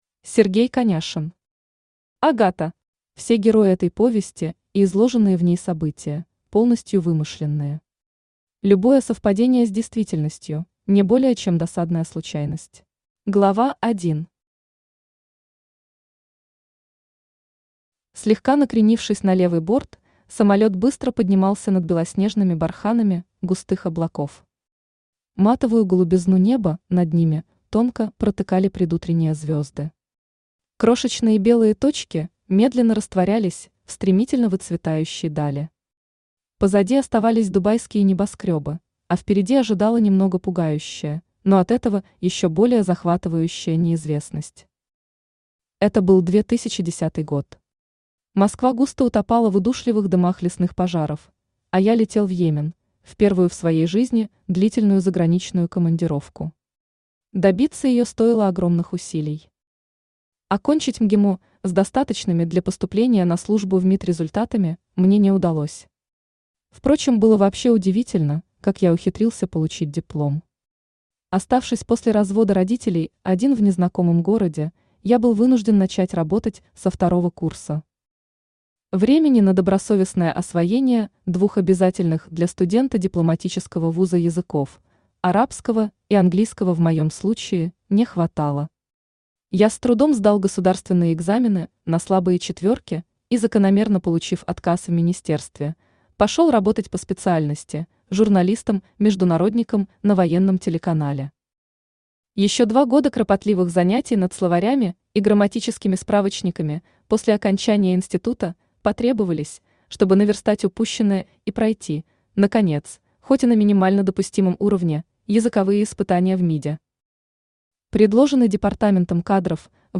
Aудиокнига Агата Автор Сергей Сергеевич Коняшин Читает аудиокнигу Авточтец ЛитРес.